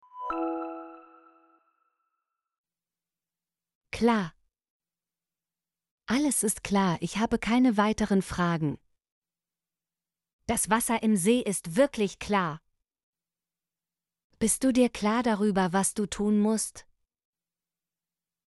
klar - Example Sentences & Pronunciation, German Frequency List